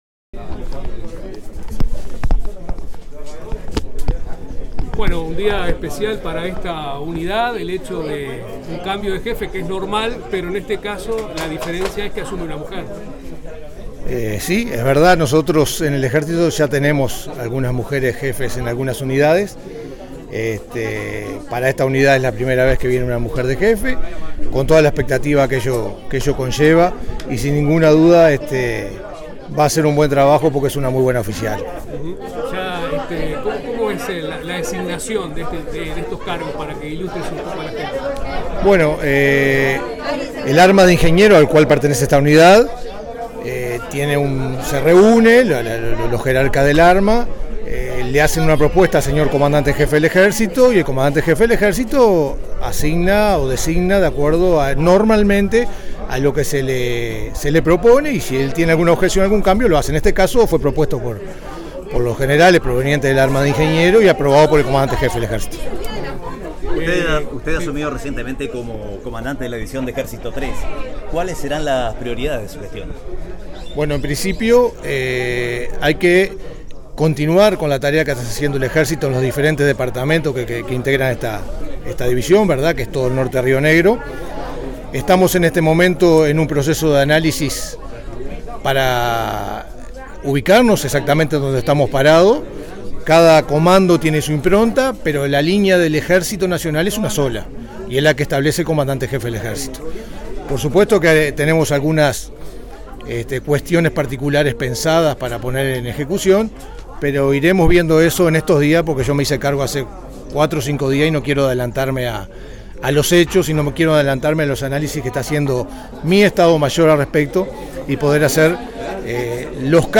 Estuvo presente en la ceremonia de relevo el Señor Comandante de la División del Ejército III Gral. Mario Stevenazzi, quien al finalizar el acto brindó expresiones a la prensa isabelina.